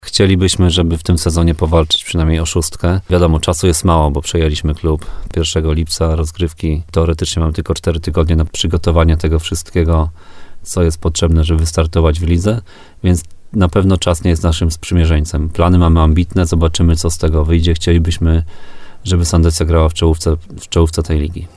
[ROZMOWA]